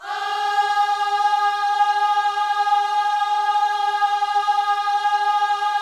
OHS G#4A  -L.wav